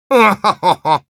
Spy_laughshort03_ru.wav